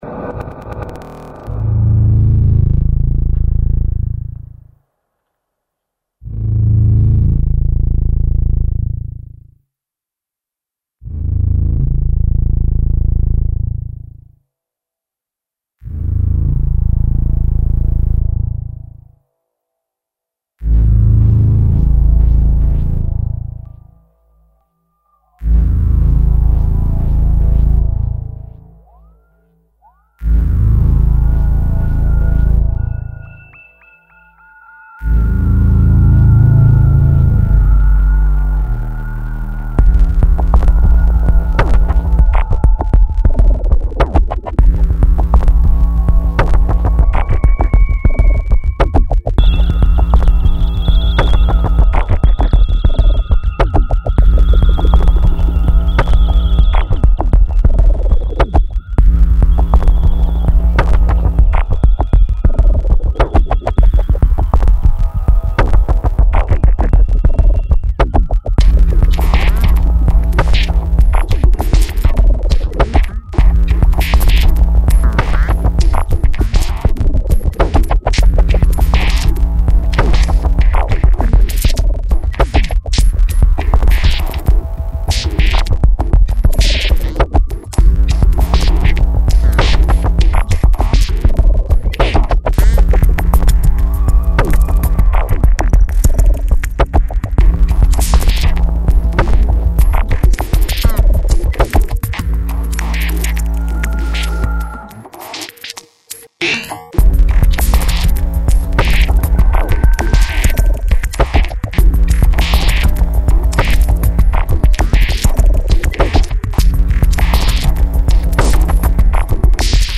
File under: Ambient-Electronica / IDM / Drill'n'Bass